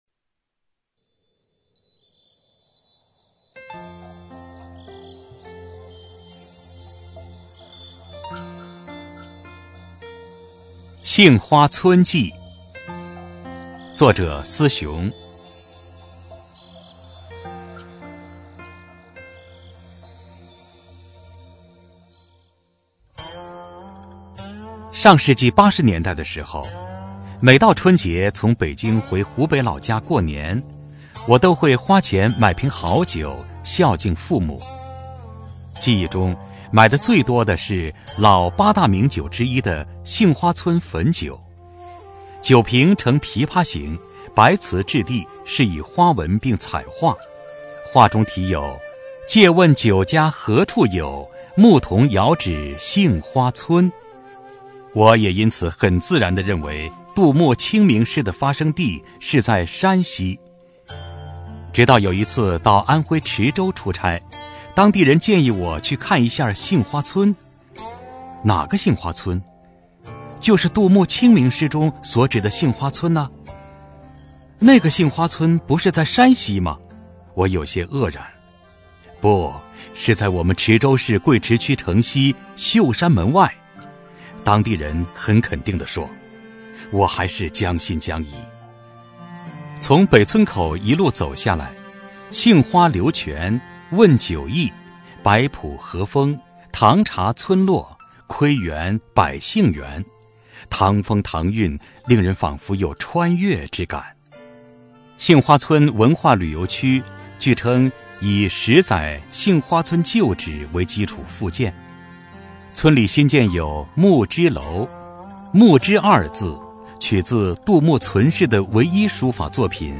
朗读